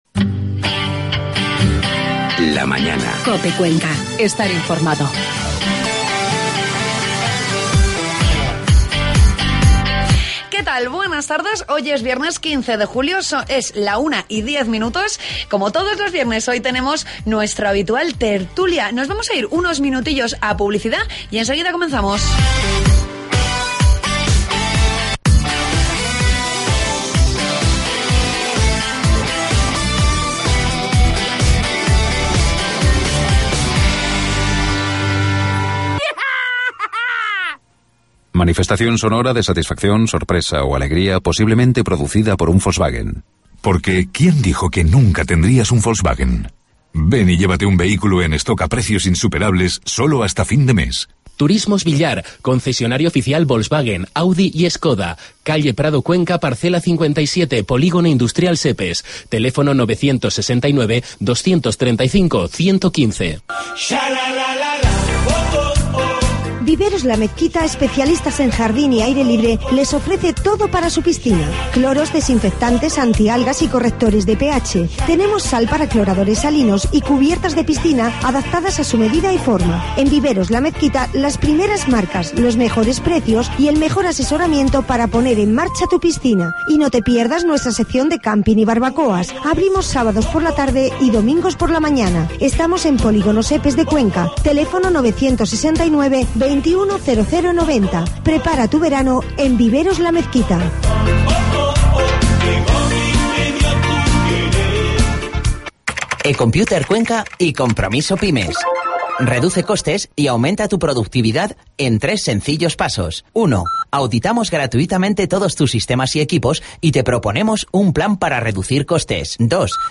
AUDIO: Ya puedes escuchar de la tertulia de COPE Cuenca de este viernes 12 de julio